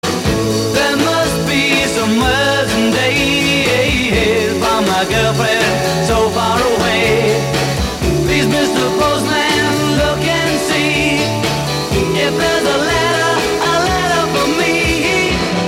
Звучить музичний фрагмент.